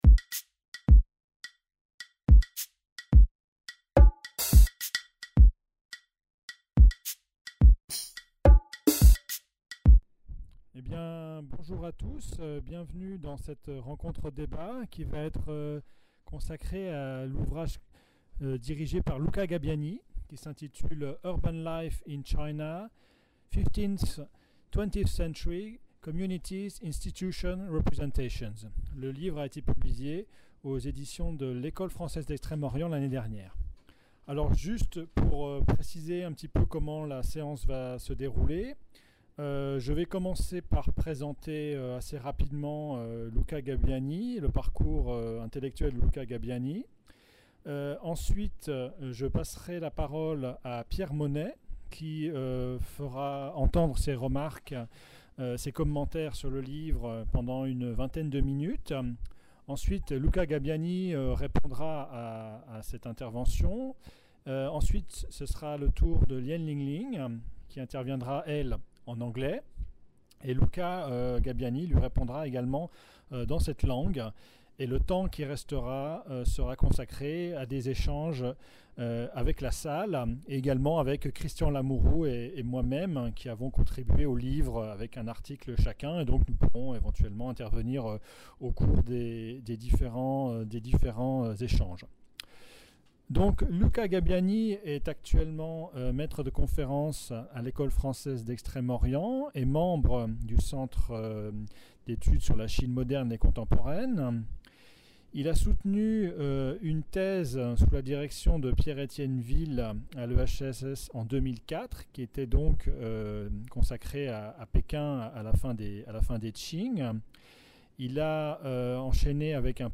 Rencontre-débat : Urban Life in China, 15th-20th centuries | Canal U